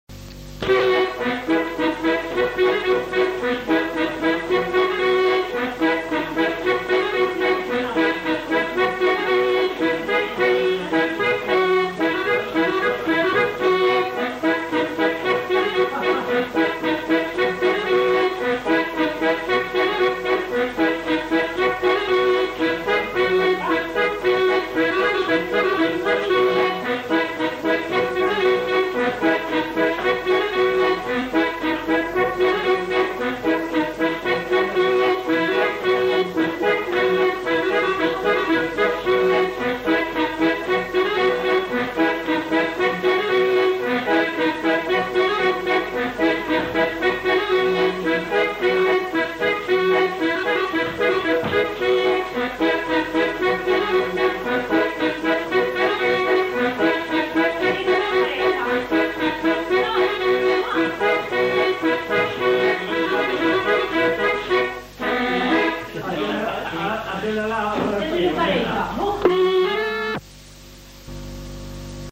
Répertoire de danses joué à l'accordéon diatonique
enquêtes sonores